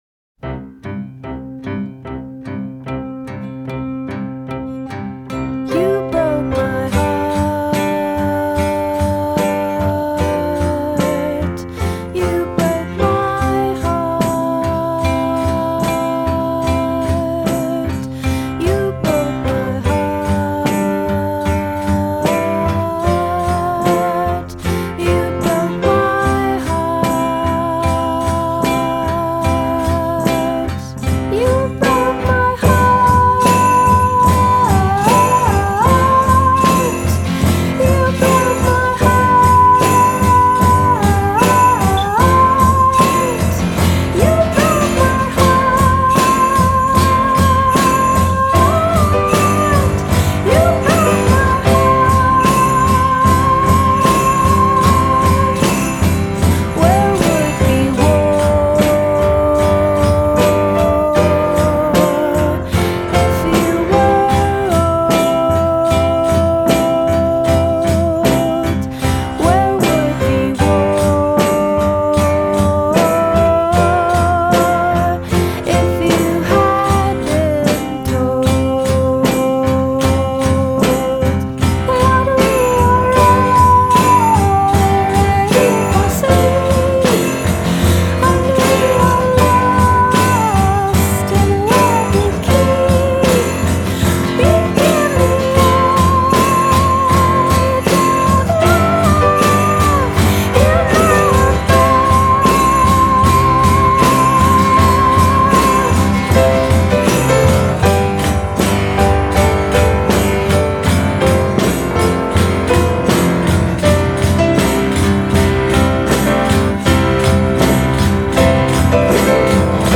a group of sunny balladeers from Southern California